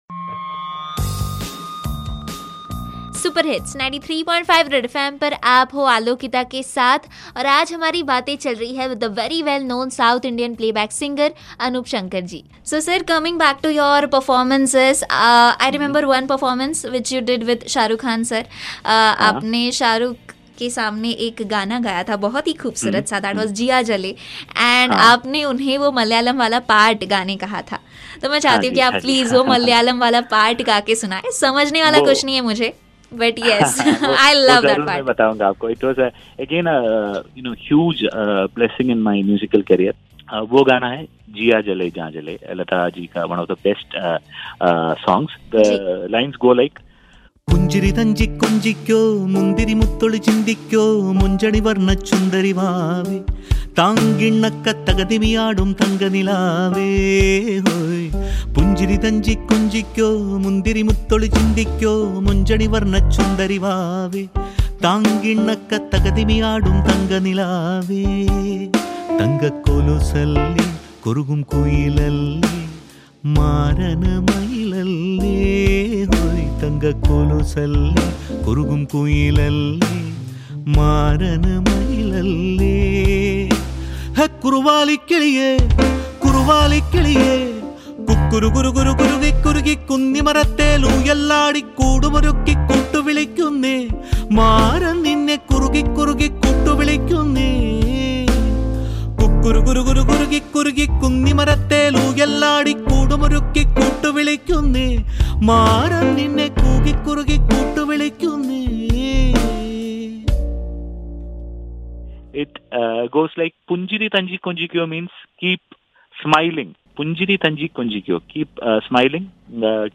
He is blessed with a mesmerizing voice.
unplugged version